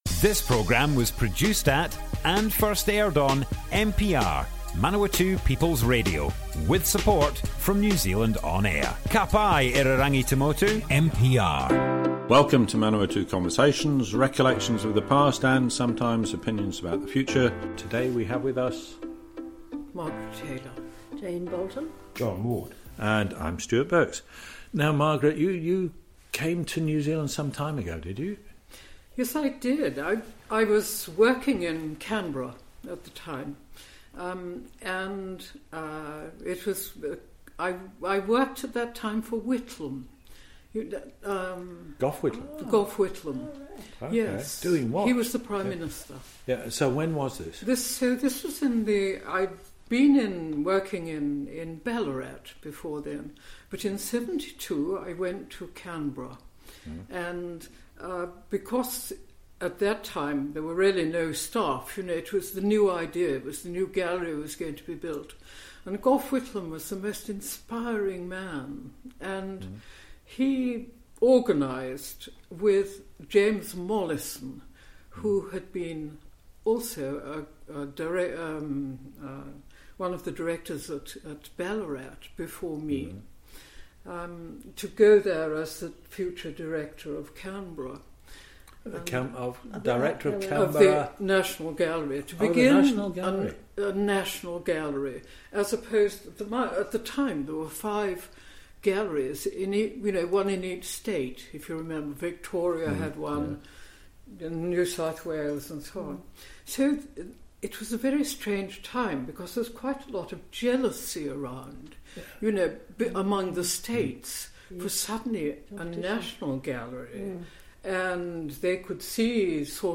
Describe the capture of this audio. Manawatu Conversations Object type Audio More Info → Description Broadcast on Manawatu People's Radio 5th January 2021.